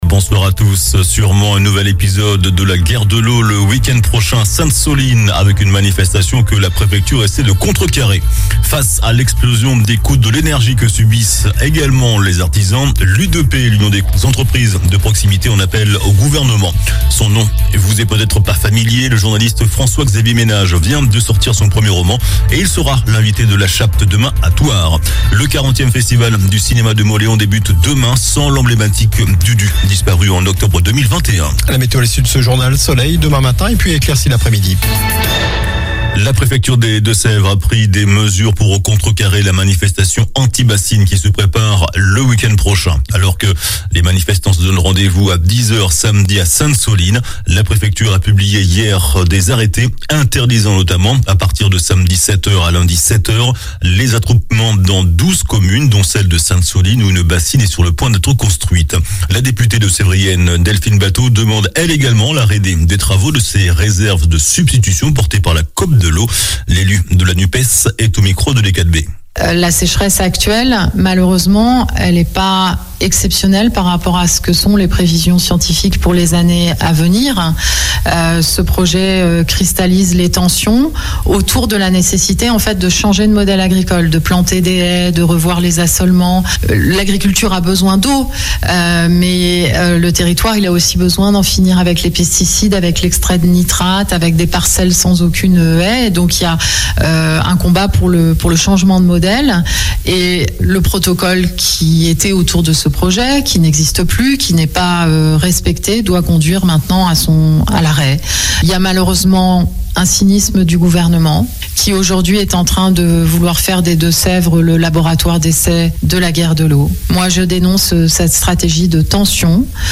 JOURNAL DU MARDI 25 OCTOBRE ( SOIR )